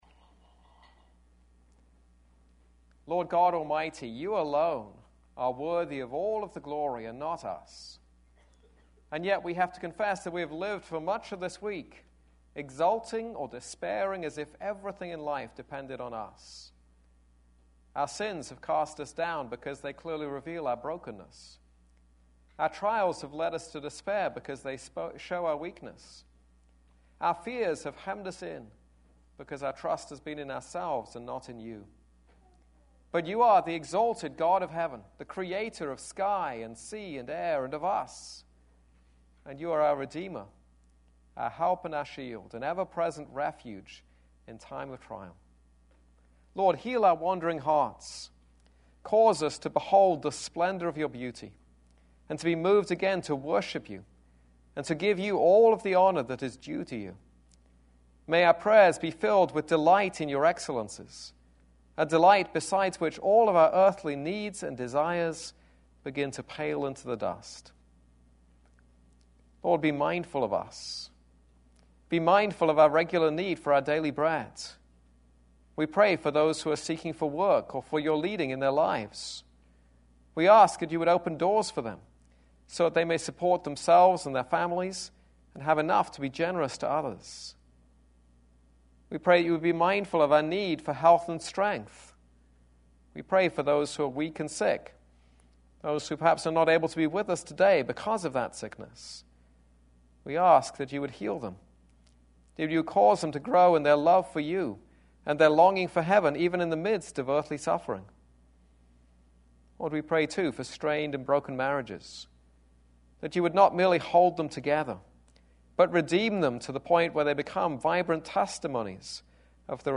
This is a sermon on Song of Songs 8:5-7.